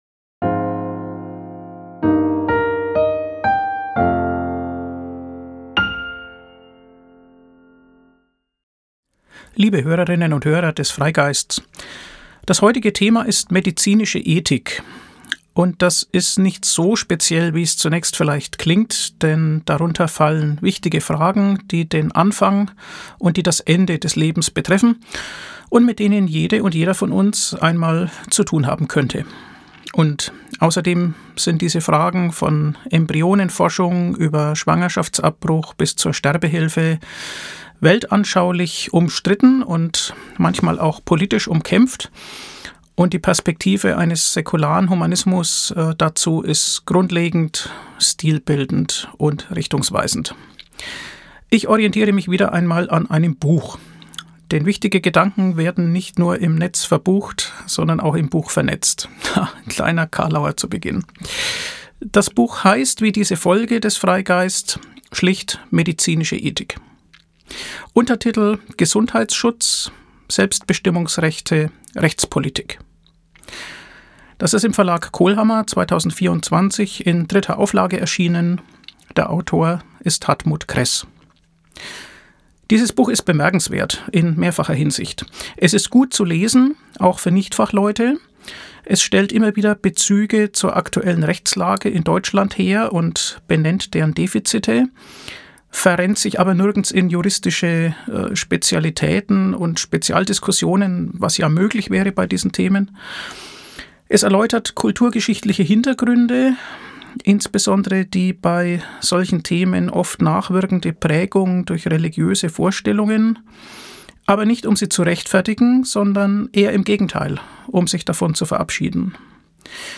Hör-Kolumne